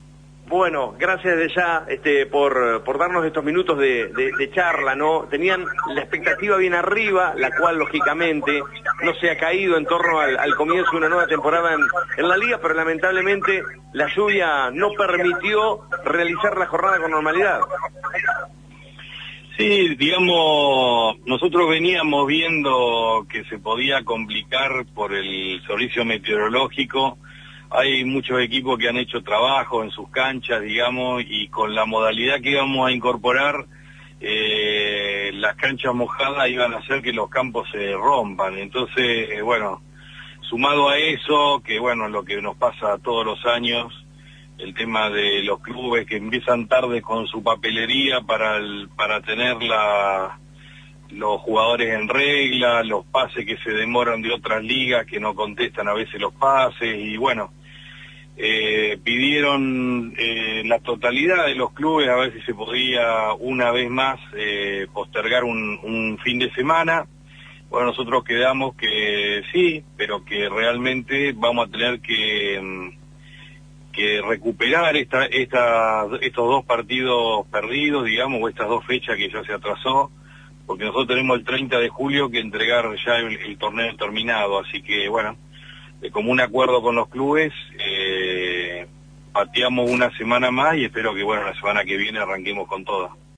Linda charla